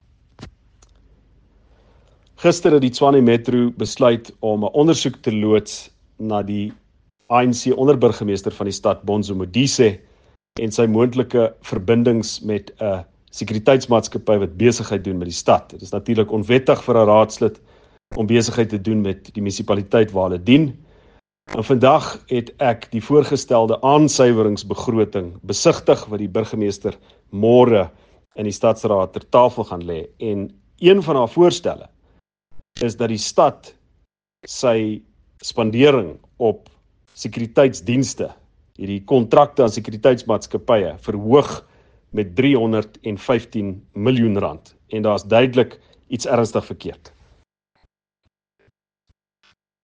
Note to Editors: Please find English and Afrikaans soundbites by Ald Cilliers Brink here and